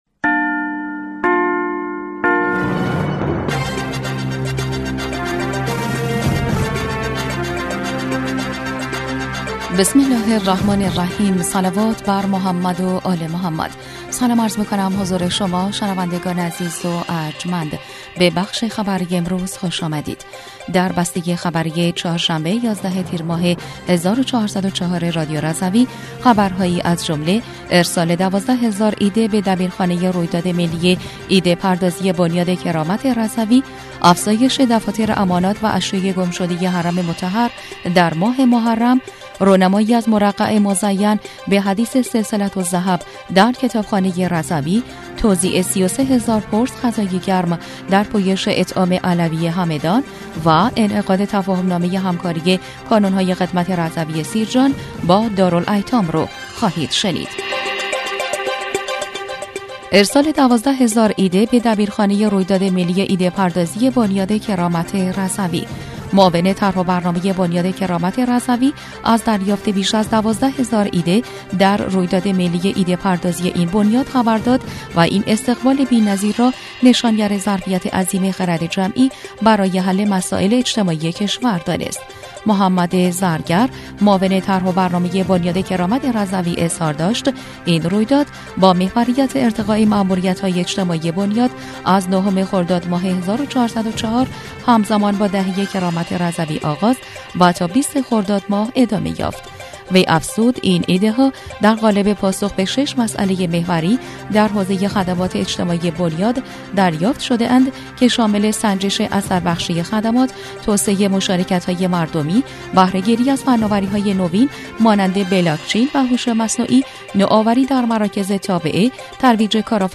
بسته خبری ۱۱ تیرماه ۱۴۰۴ رادیو رضوی/